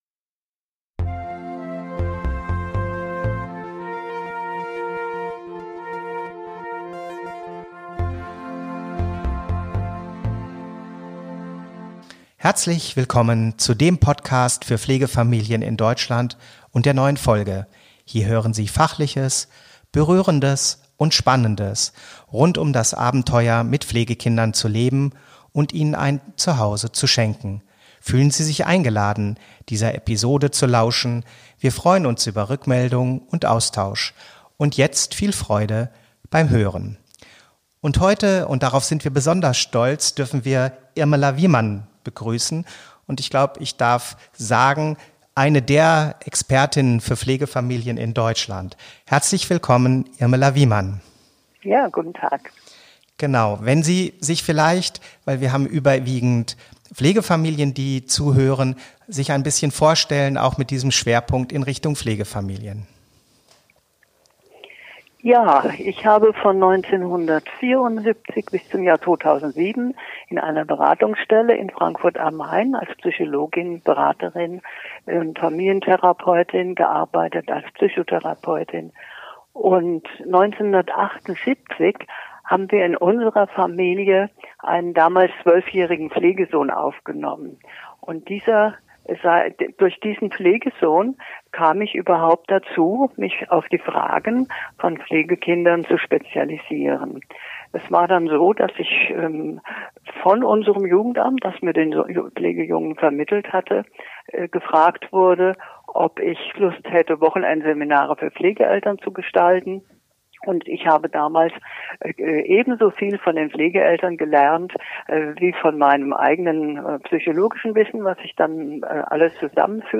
Sie lebt durch und durch für Pflegekinder und es geht ihr darum, die Kinder und Pflegefamilien dabei zu unterstützen, dass es gut gelingt mit zwei Elternpaaren und zwei Familien leben zu können. Ein beeindruckendes und berührendes Gespräch, das wir allen Pflegefamilien und Herkunftsfamilien ans Herz legen.